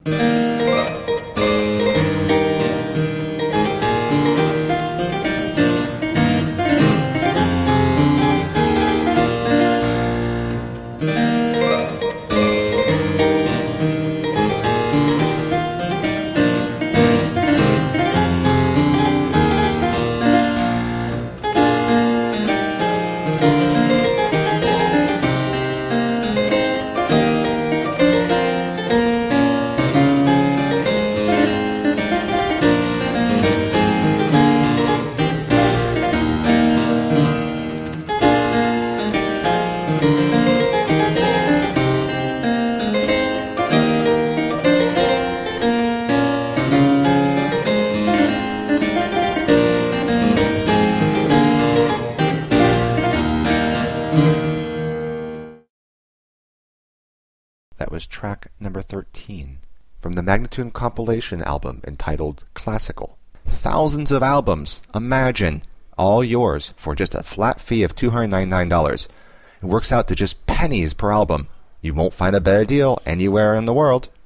Suite in F major (Courante)